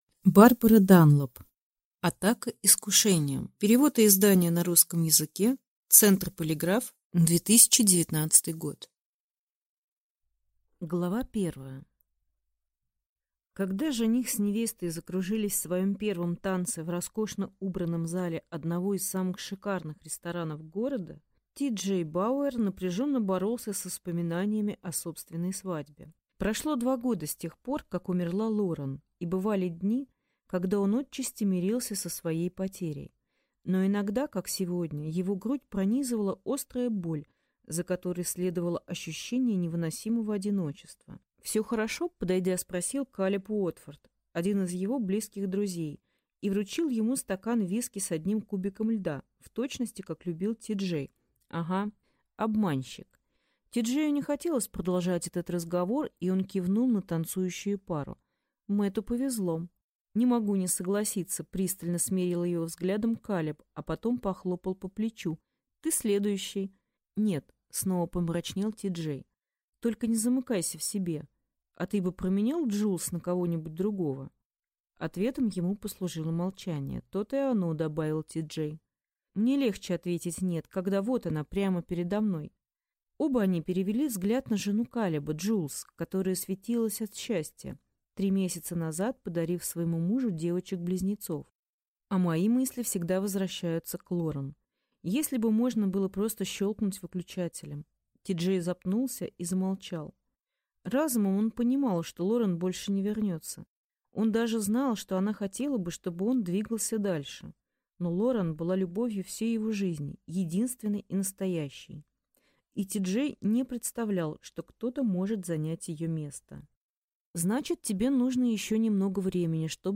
Аудиокнига Атака искушением | Библиотека аудиокниг